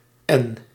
Ääntäminen
Ääntäminen letter name: IPA: /ɛn/ Haettu sana löytyi näillä lähdekielillä: hollanti Käännöksiä ei löytynyt valitulle kohdekielelle.